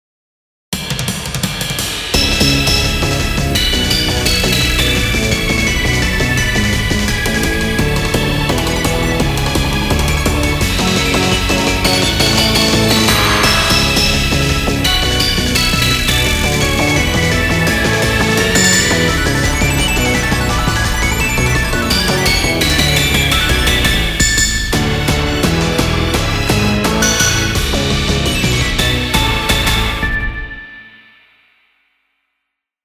ダークが色濃いシリーズです。
inst